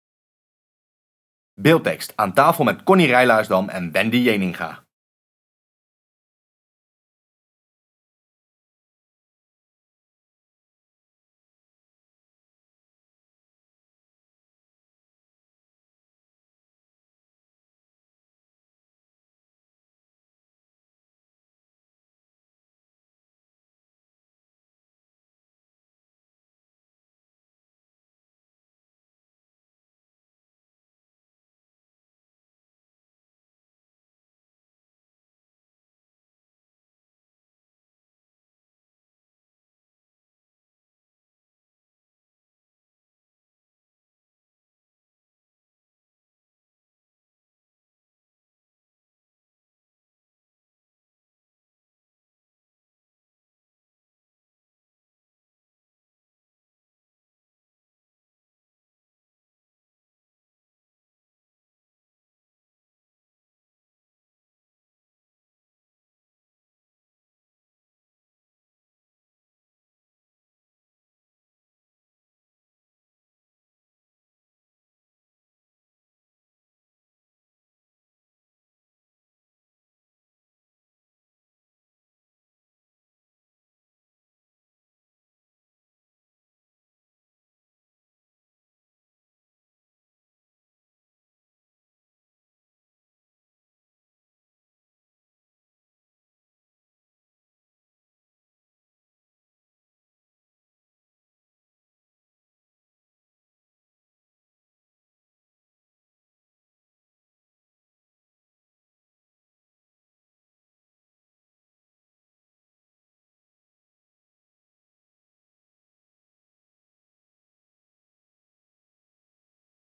*Zachte muziek speelt*